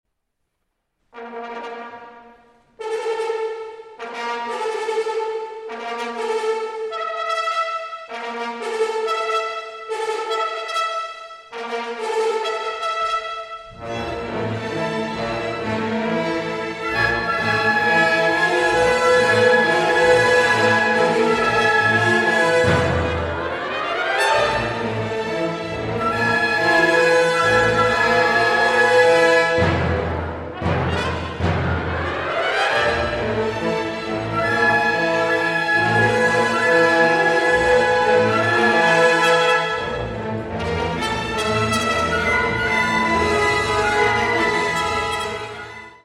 First Recordings, Live